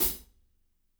-18  CHH A-R.wav